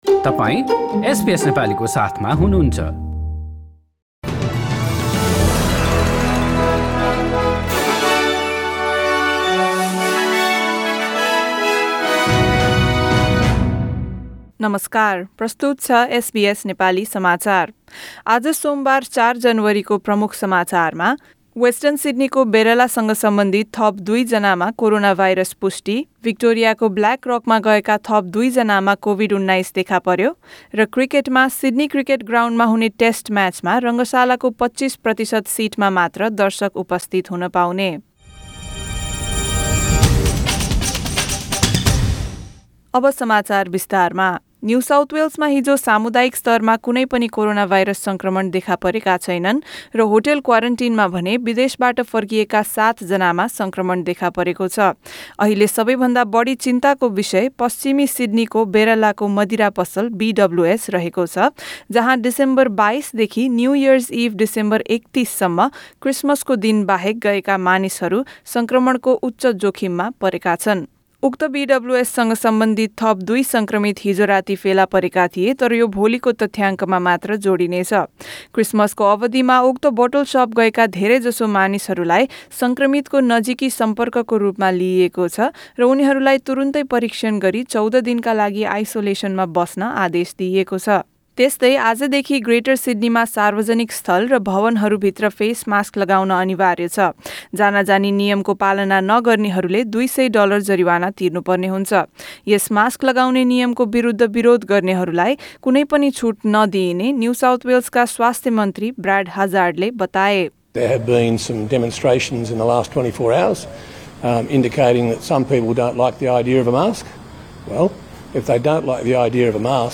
एसबीएस नेपाली अस्ट्रेलिया समाचार: सोमबार ४ जनवरी २०२१